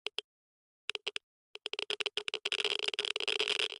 Minecraft Version Minecraft Version latest Latest Release | Latest Snapshot latest / assets / minecraft / sounds / ambient / nether / basalt_deltas / click8.ogg Compare With Compare With Latest Release | Latest Snapshot
click8.ogg